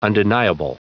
Prononciation du mot undeniable en anglais (fichier audio)
Prononciation du mot : undeniable